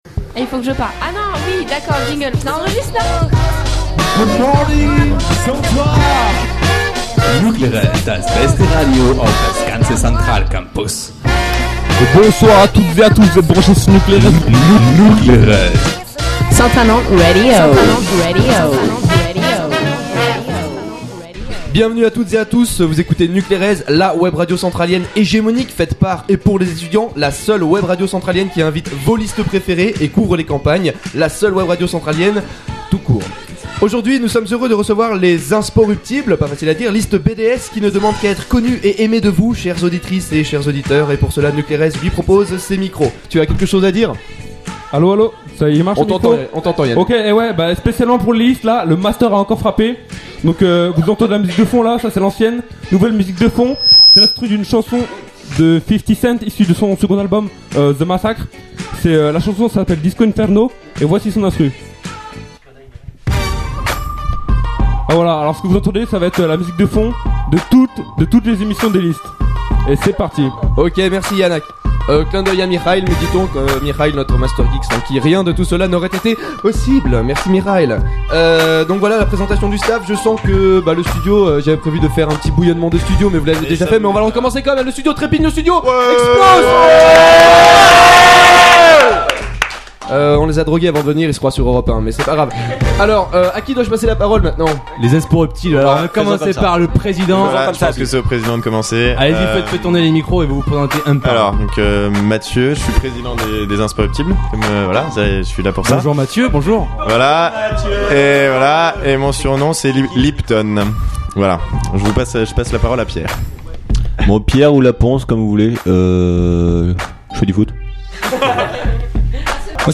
Chères auditrices, chers auditeurs, Nuclérez revient pour cette deuxième partie des campagnes 2011 ! Cette fois-ci, ce sont les deux listes BDE concurrentes qui sont venues s'exprimer au micro de votre radio préférée.